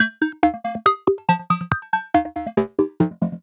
tx_synth_140_minwaze_CMin1.wav